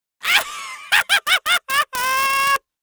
Wild Laughs Male 01
Wild Laughs Male 01.wav